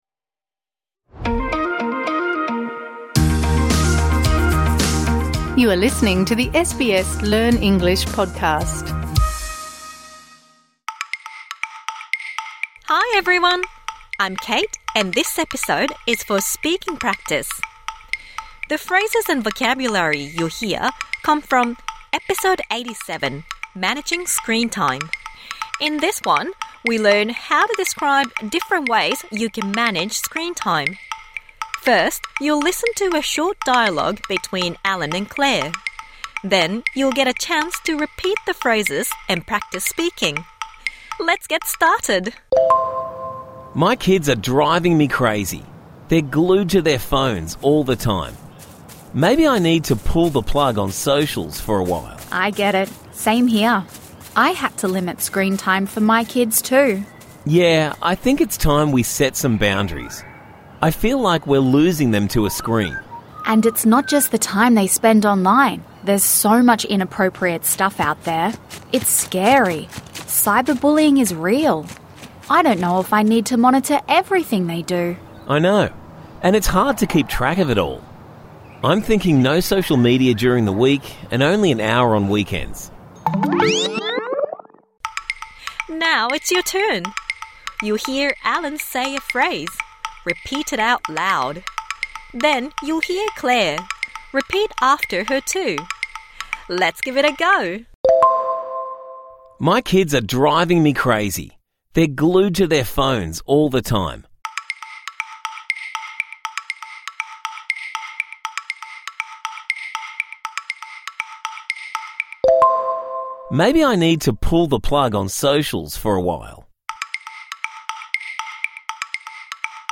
This bonus episode provides interactive speaking practice for the words and phrases you learnt in Episode #87 Managing screen time (Med).